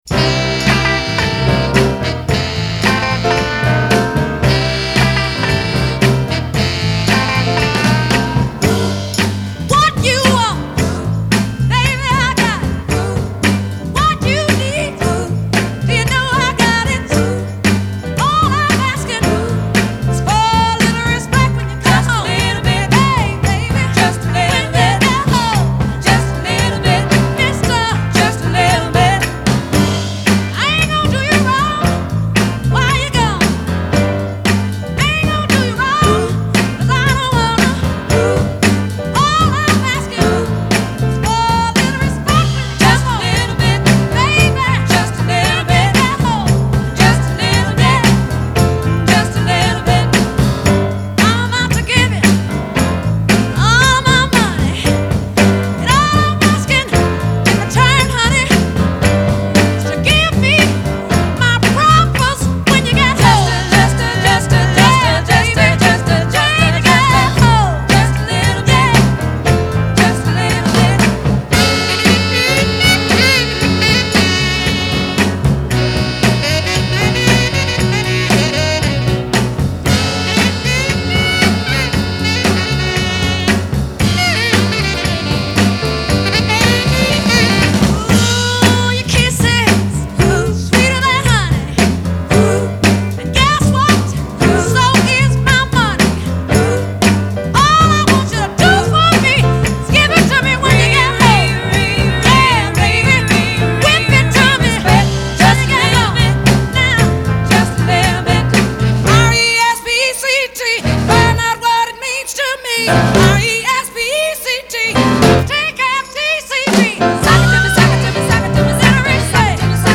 Genre : Soul, Blues